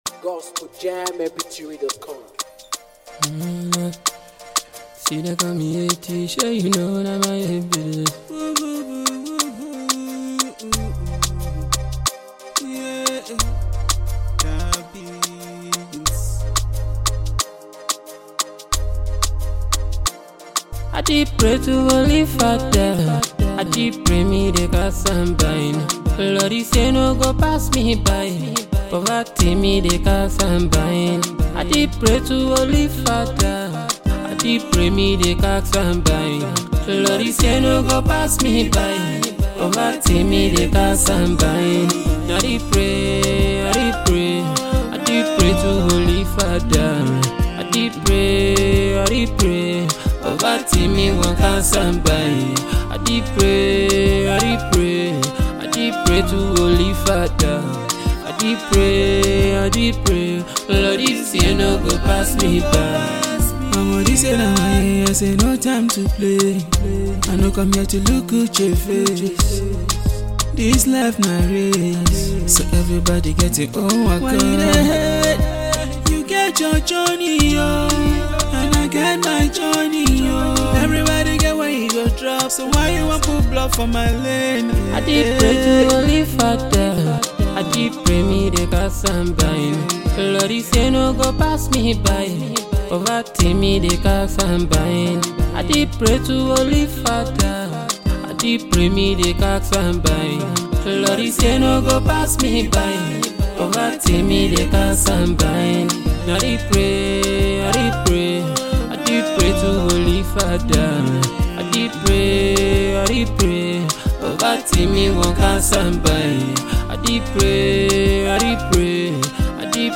Afro beatmusic